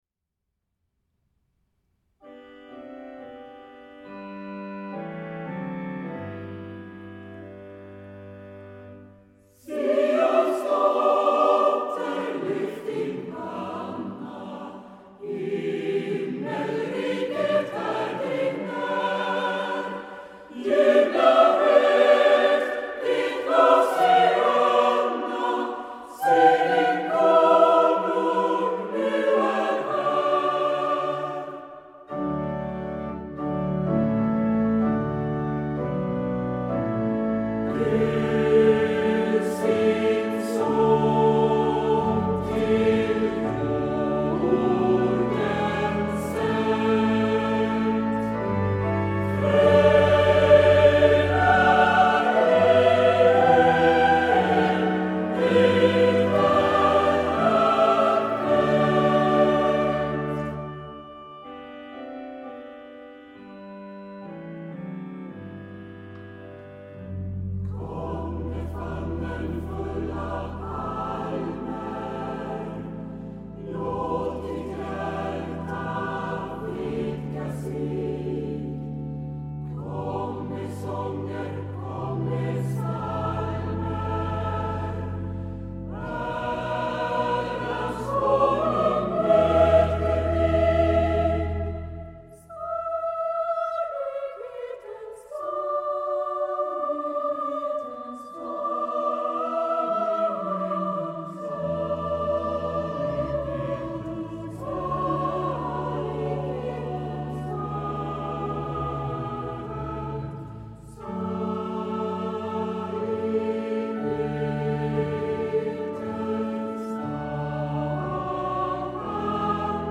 Blandad Stockholmskör